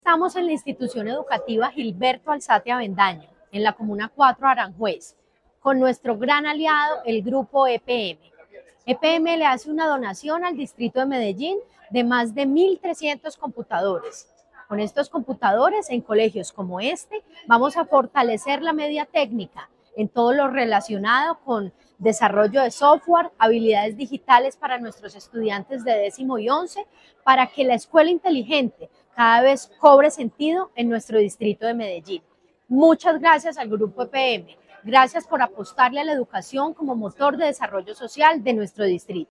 Declaraciones de la secretaria de Educación, Carolina Franco Giraldo
Declaraciones-de-la-secretaria-de-Educacion-Carolina-Franco-Giraldo-1.mp3